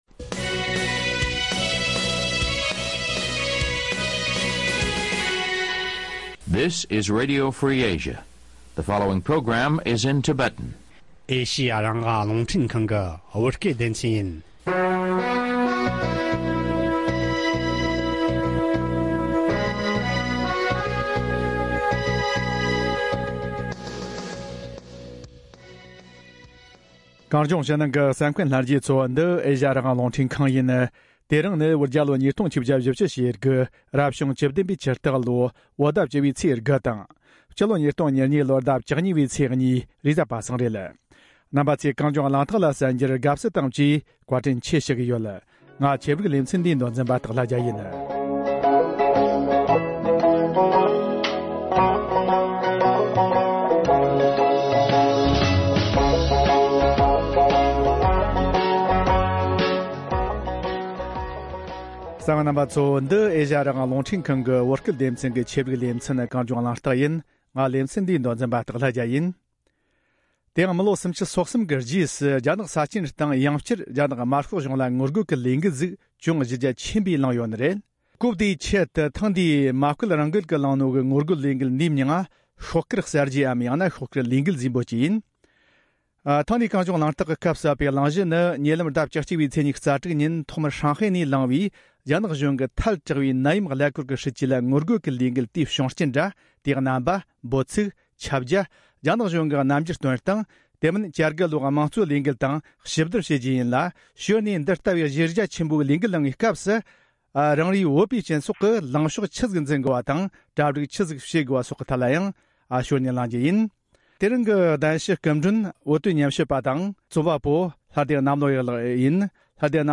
བགྲོ་གླེང་ཞུས་པའི་ལས་རིམ།